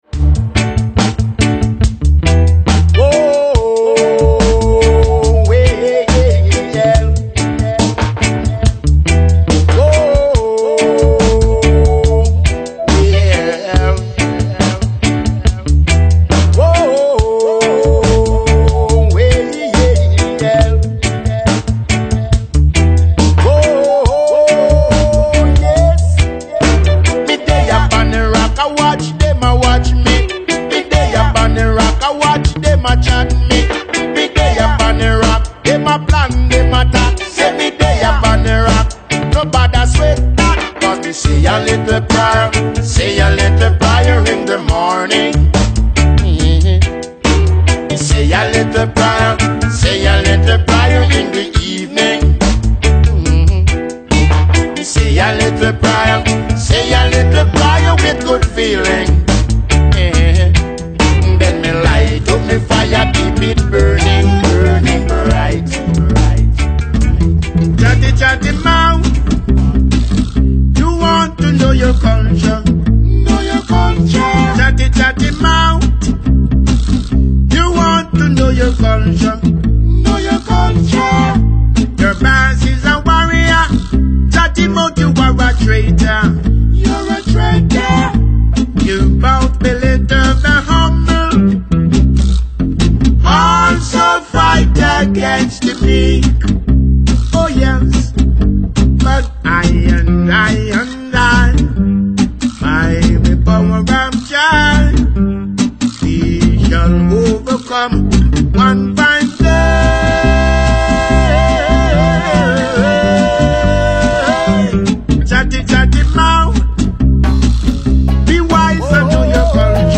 quick 10-minute mix